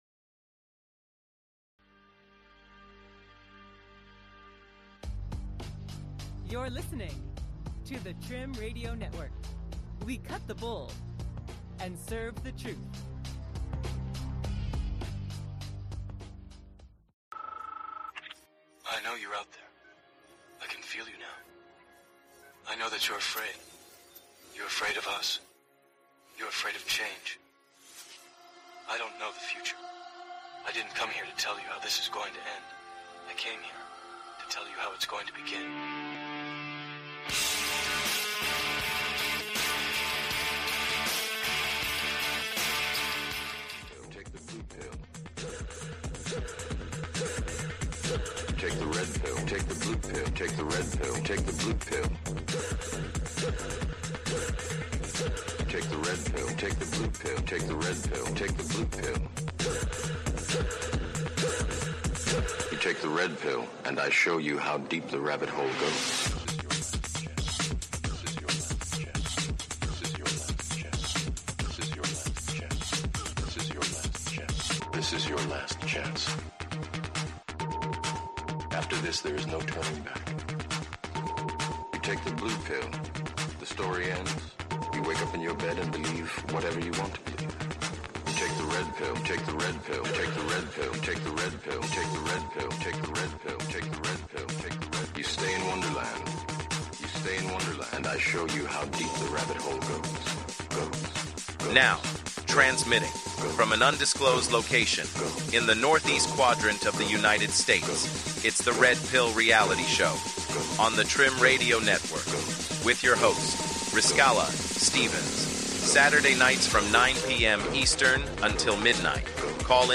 TRIM Radio